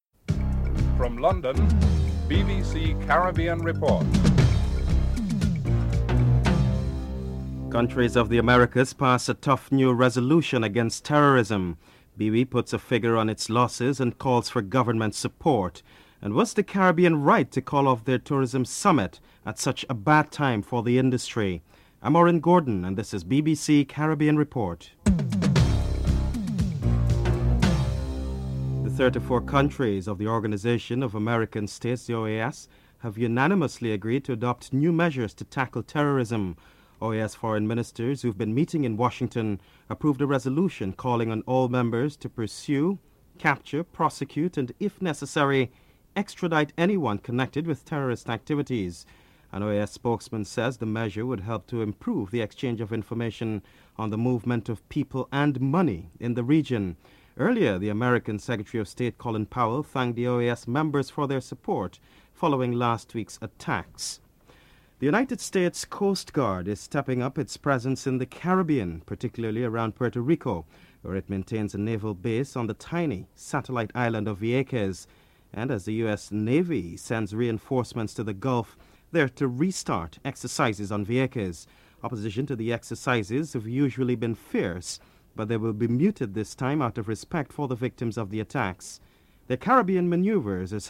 1. Headlines (00:00-00:28)
Attorney General of the Bahamas Carl Bethel, Prime Minister Hubert Ingraham and Tourism Minister McKeeva Bush are interviewed.